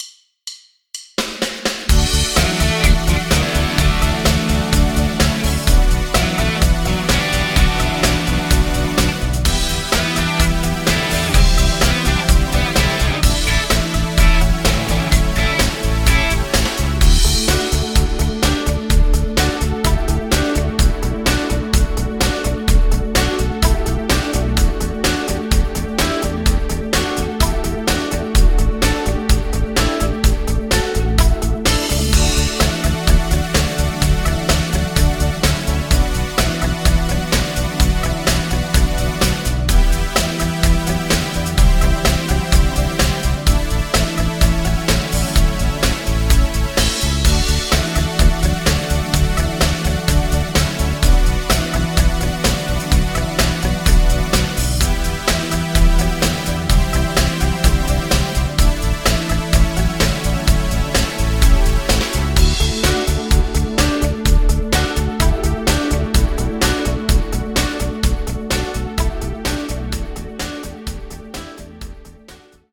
Playbacks, Karaoke, Instrumental